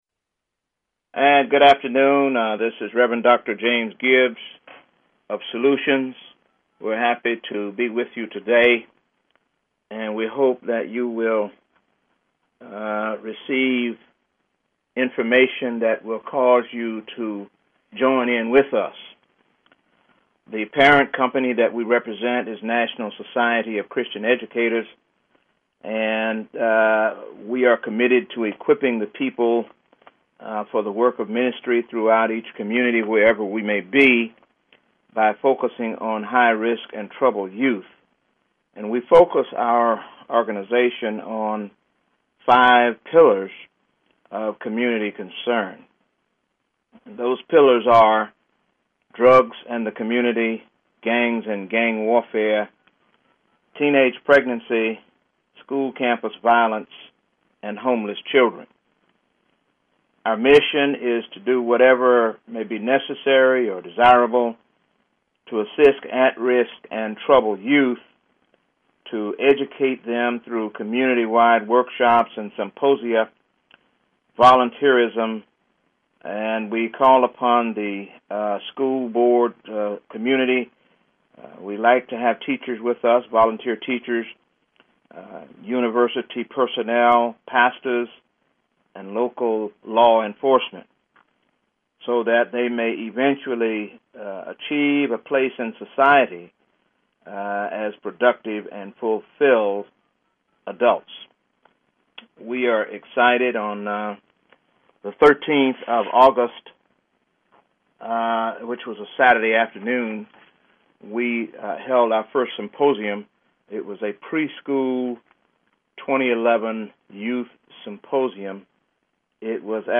Talk Show Episode, Audio Podcast, Solutions and Courtesy of BBS Radio on , show guests , about , categorized as